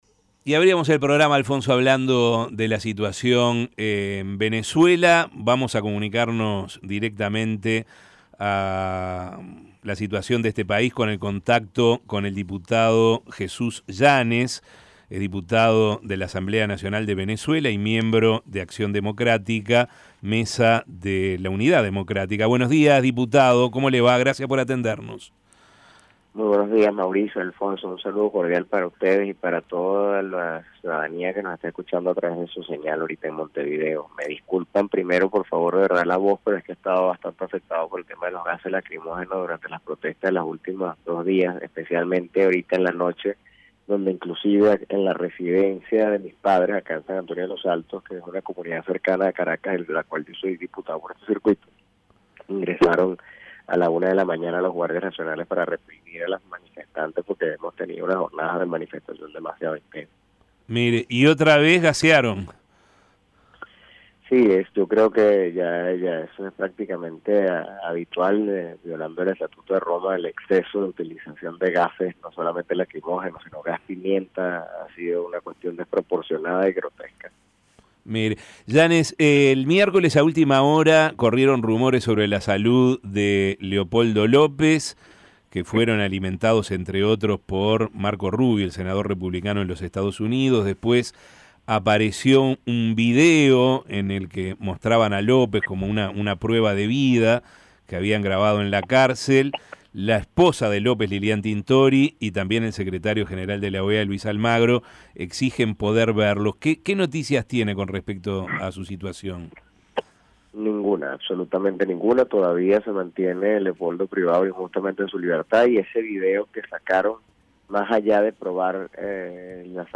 "No hay ninguna novedad con respecto a la situación de Leopoldo López (el preso político más renombrado de Venezuela) y hay dudas con respecto a la veracidad del video que presentó Diosdado Cabello como prueba de vida", dijo a El Espectador Jesús Yánez, diputado venezolano de la Mesa de la Unidad Democrática.
"En Venezuela se viven situaciones injustas y humillantes"; agregó el legislador con la voz afectada por los gases lacrimógenos arrojados anoche en una manifestación en su barrio que fue reprimida por la Guardia Nacional Bolivariana.